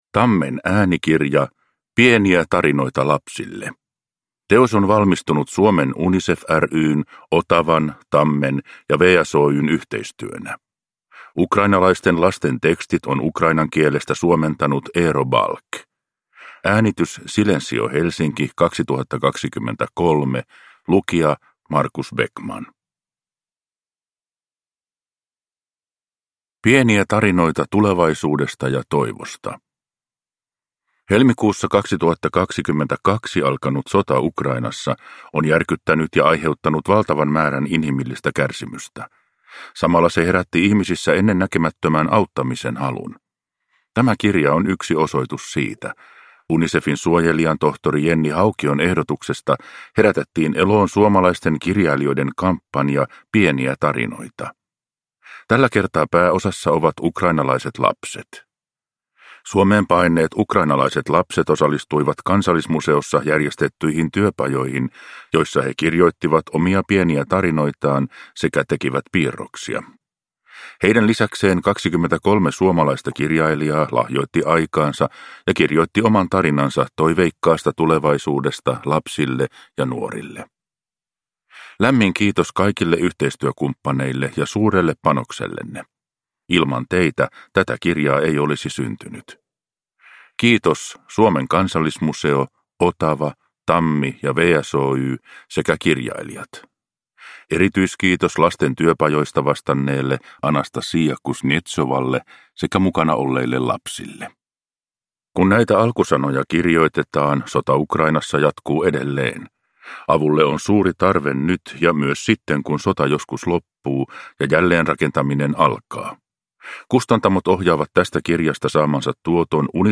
Pieniä tarinoita lapsille – Ljudbok – Laddas ner